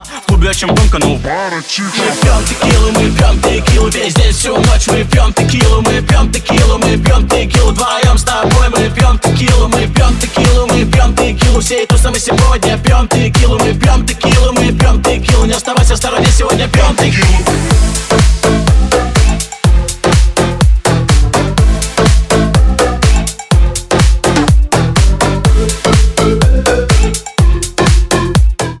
Рингтоны » клубные